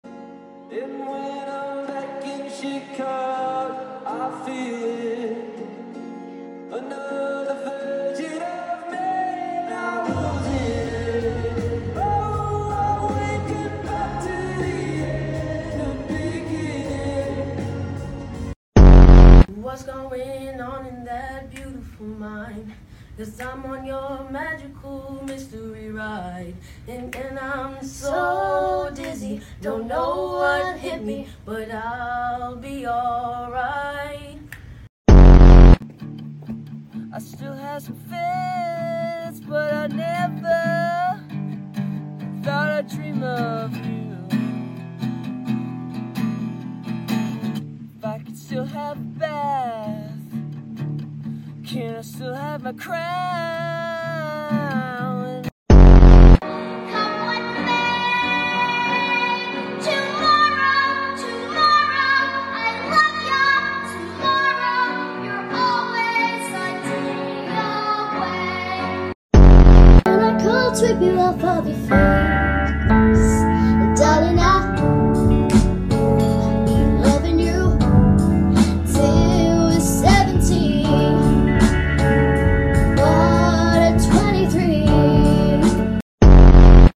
Stranger things casts singing moments.